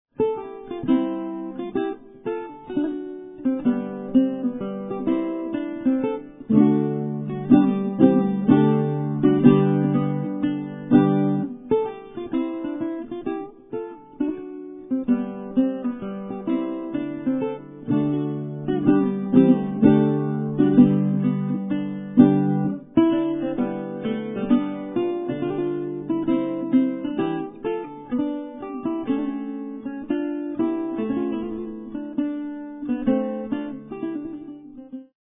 he plays the seldom heard Baroque guitar